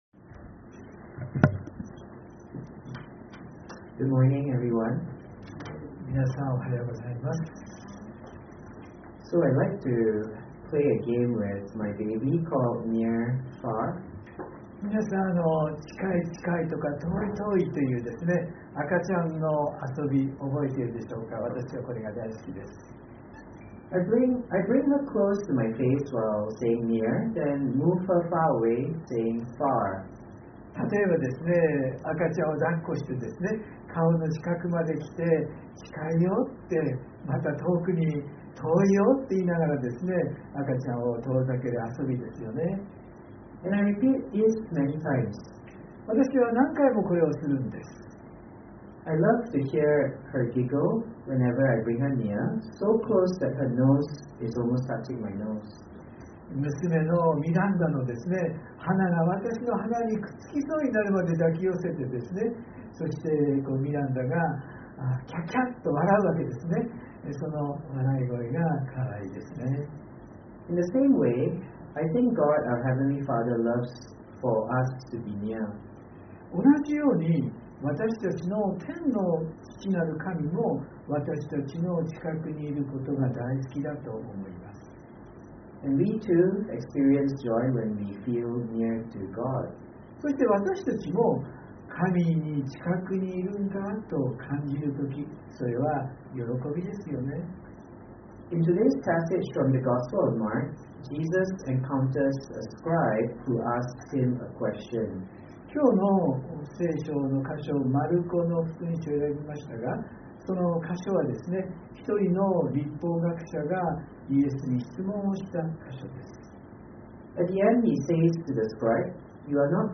↓メッセージが聞けます。（日曜礼拝録音） 【iPhoneで聞けない方はiOSのアップデートをして下さい】 「近い遠い」という赤ちゃんとのふれあい遊びがあって、私はこれが好きなんです。